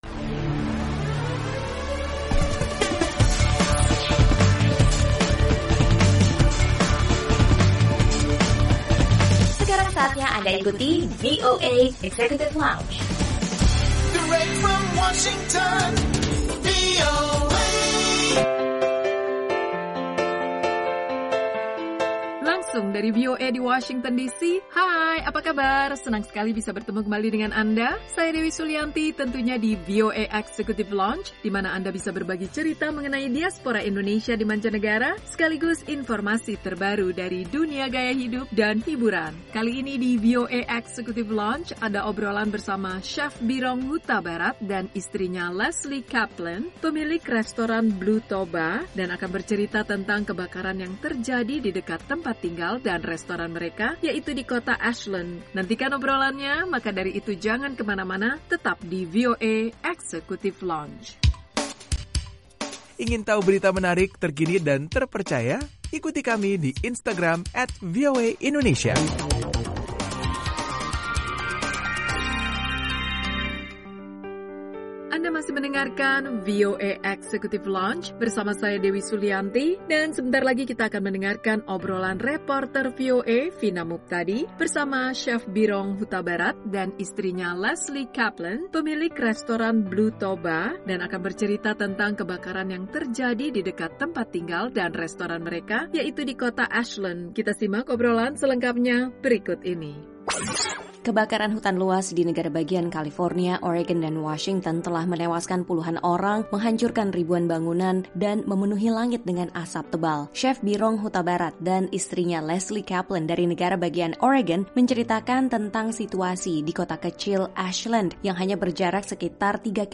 Obrolan